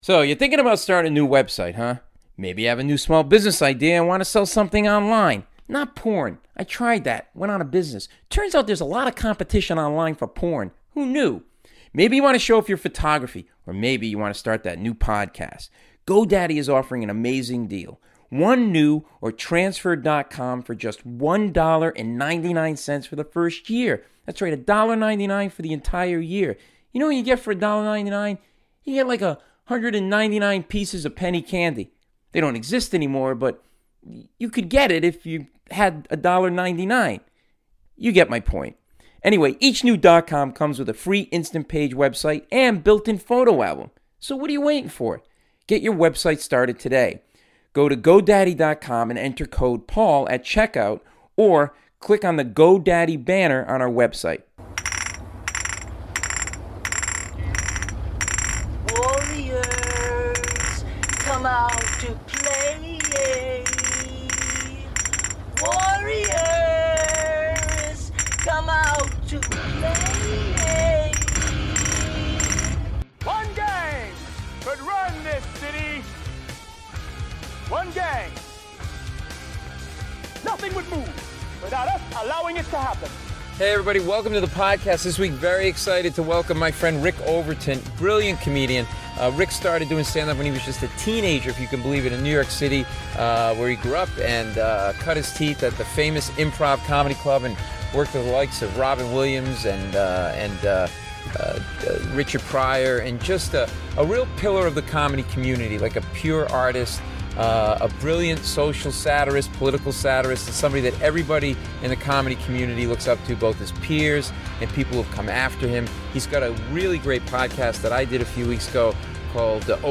I talk with comedian and Emmy Award Winning Writer, Rick Overton.